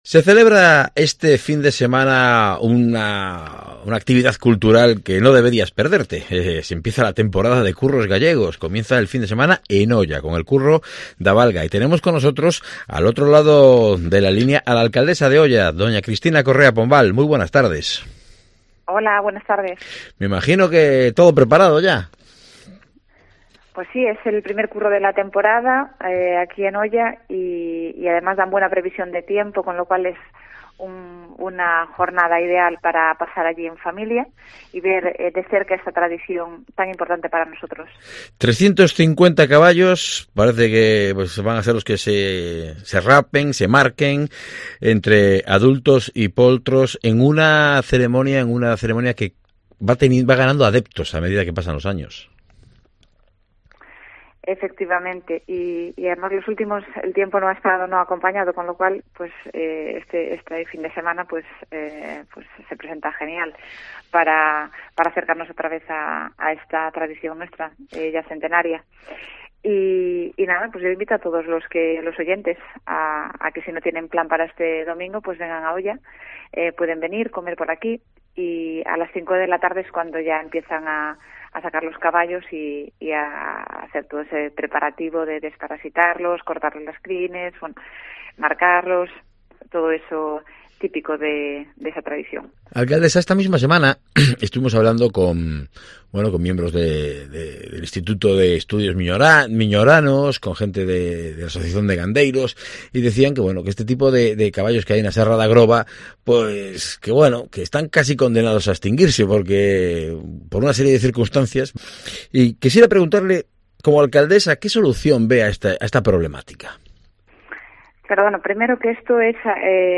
Entrevista con Cristina Correa, alcaldesa de Oia